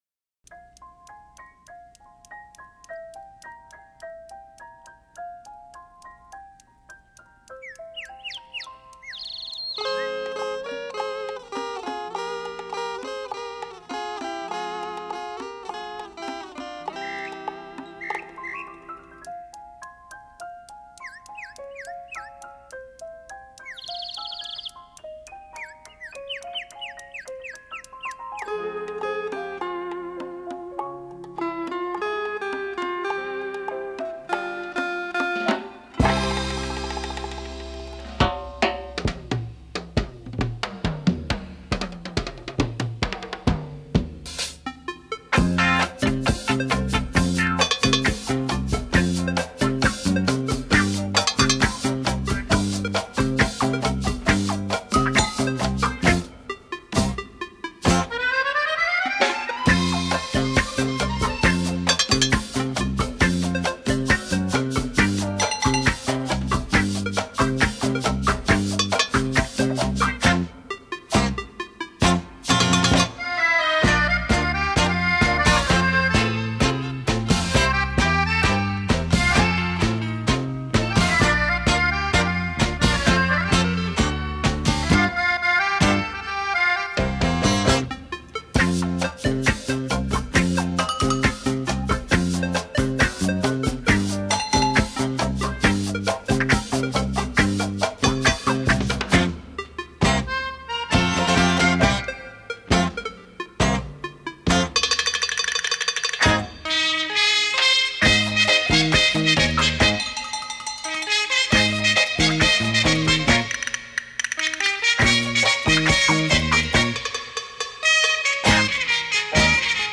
24BIT